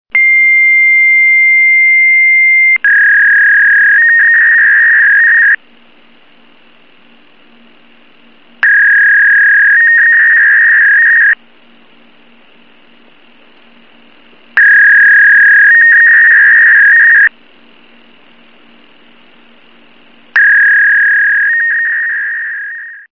When callers call your fax box they will hear the normal DTMF fax tones they hear when calling a fax machine.
• Fax tone when your number is dialed directly to give that corporate image of a dedicated fax line.